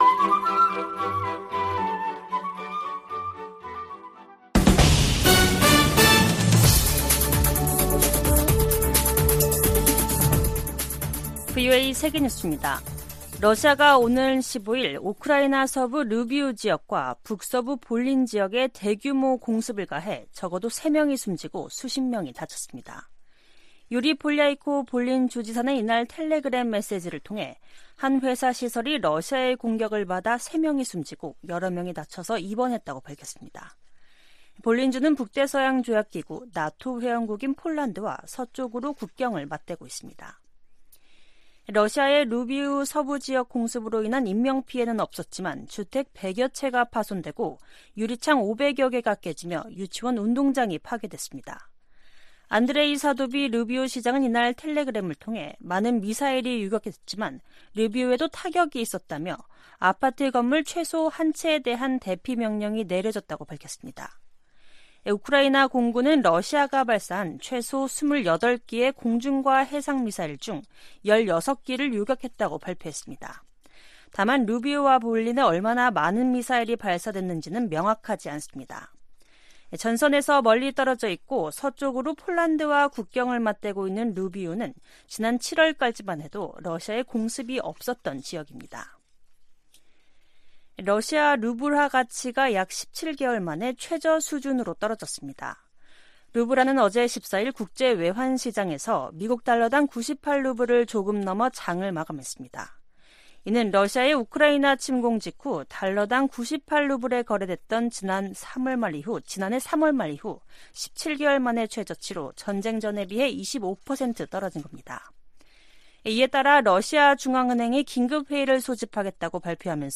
VOA 한국어 간판 뉴스 프로그램 '뉴스 투데이', 2023년 8월 15일 2부 방송입니다. 18일 캠프 데이비드 미한일 정상회의에서 3국 협력을 제도화하는 방안이 나올 것으로 전망되고 있습니다. 미 국방부는 미한일 군사훈련 정례화 문제와 관련해 이미 관련 논의가 있었음을 시사했습니다. 윤석열 한국 대통령은 광복절 경축사에서 북한에 대한 ‘담대한 구상’ 제안을 재확인하면서 압도적 힘에 의한 평화 구축을 강조했습니다.